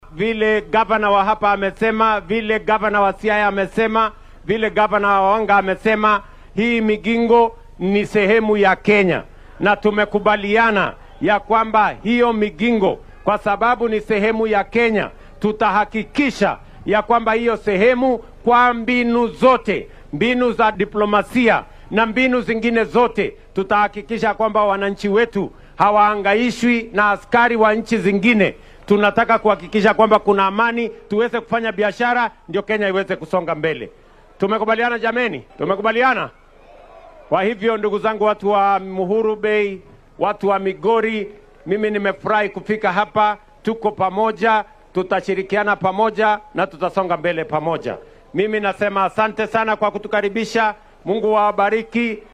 Madaxweynaha dalka William Ruto ayaa xilli uu xaflad dhaqameed uga qayb galayay deegaanka Nyatike ee ismaamulka Migori sheegay in jasiiradda Migingo Island oo horay loogu muransanaa ay ka mid tahay dhulka Kenya. Waxaa uu xusay in ay adeegsan doonaan qaab kasta oo ay dibloomaasiyad ka mid tahay si meesha looga saaro sheegashada Uganda ee jasiiraddaasi.